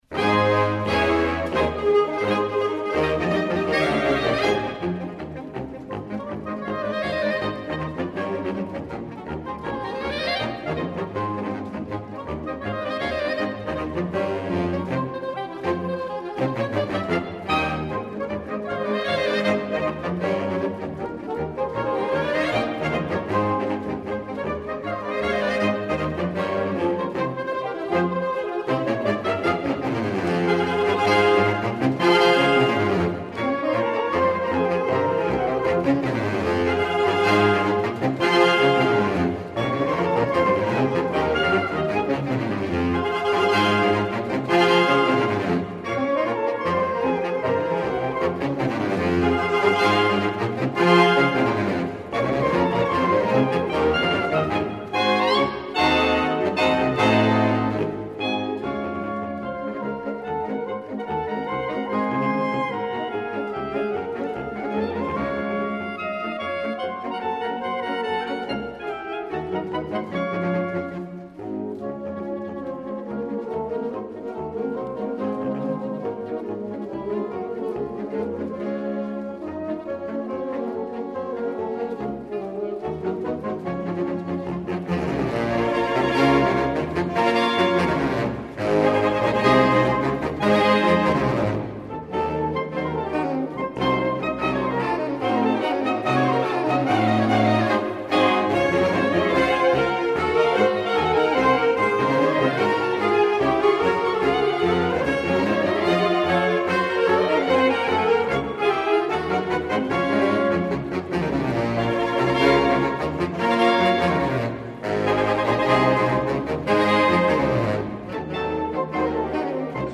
Voicing: Saxophone Choir